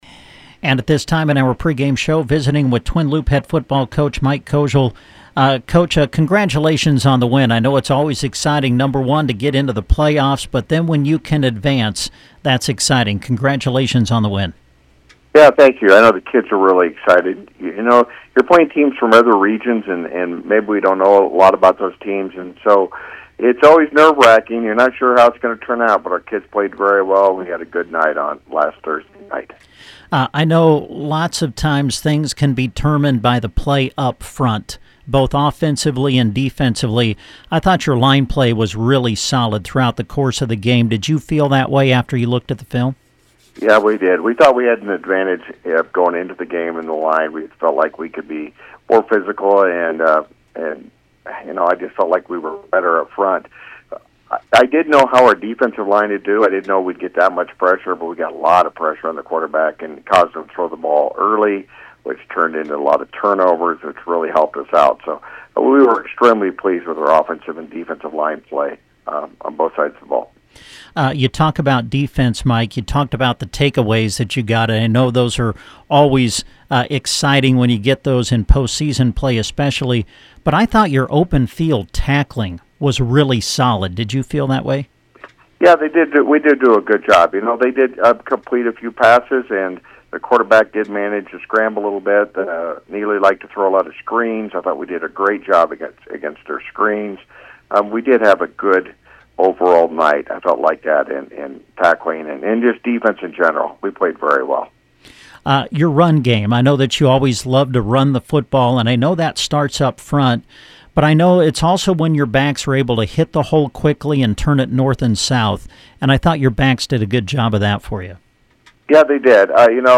INTERVIEW WITH COACH